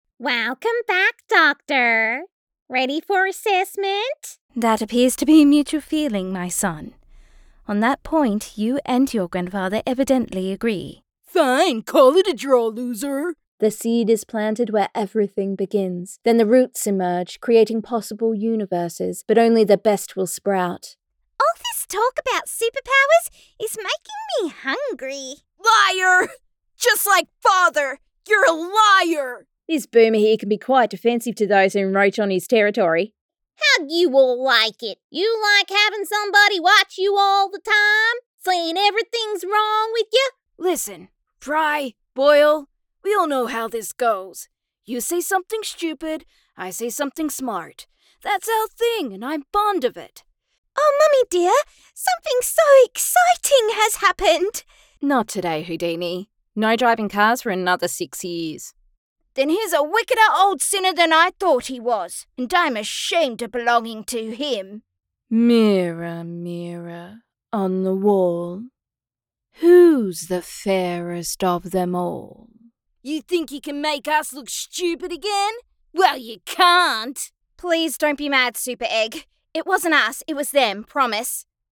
Female
Warm , versatile , dynamic and engaging . Large vocal range.
Character / Cartoon
A Variety Of Characters